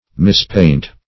Mispaint \Mis*paint"\, v. t. To paint ill, or wrongly.